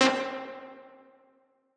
Rockin' One Shot.wav